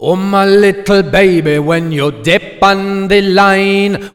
OLDRAGGA1 -R.wav